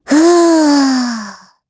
Gremory-Vox_Casting4_kr.wav